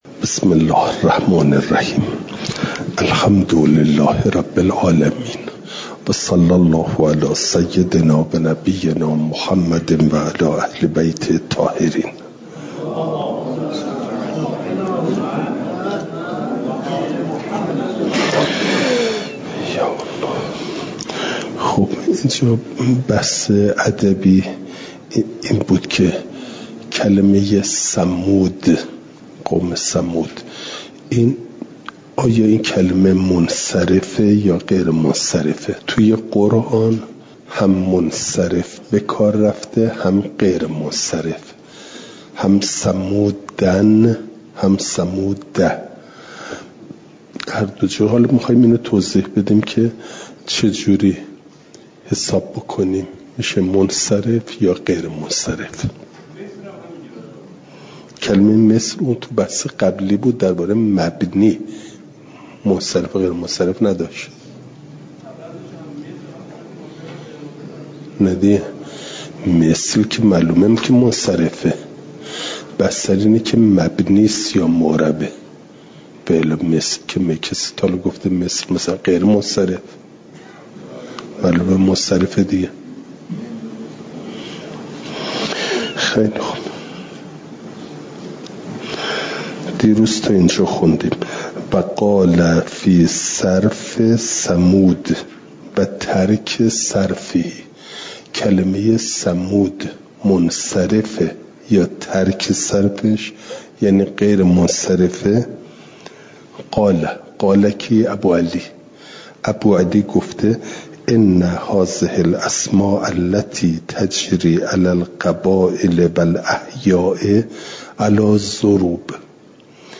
مجمع البیان | جلسه ۷۴۶ – دروس استاد